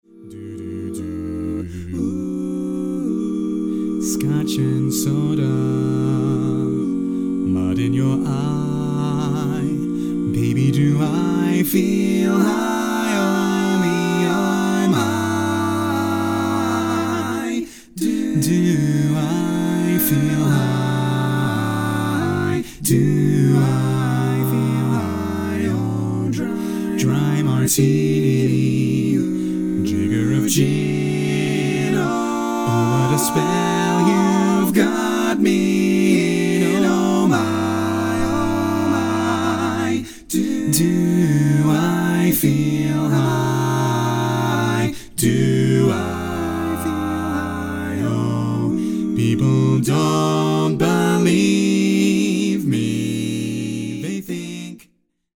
Male